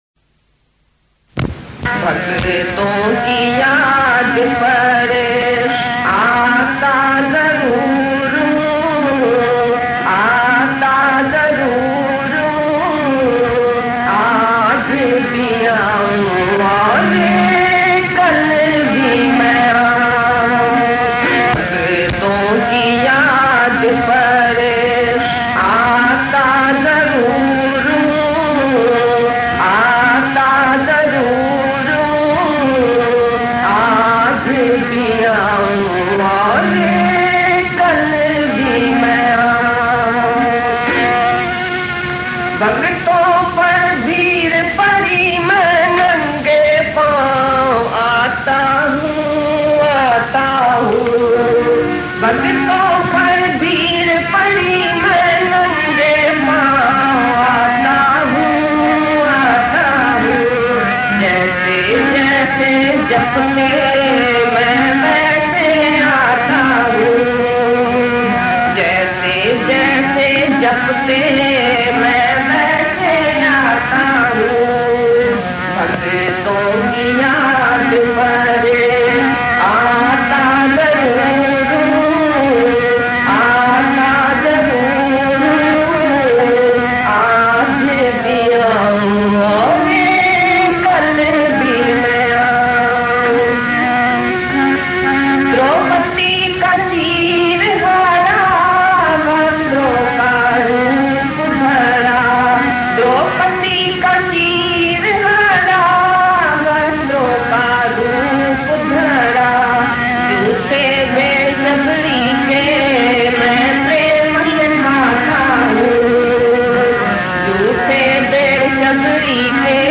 Geeta Shyam Bhajans Lyrics